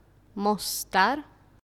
Mostar (pronounced [mǒstaːr]
Bs-Mostar.ogg.mp3